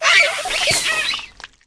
Cri de Tag-Tag dans Pokémon HOME.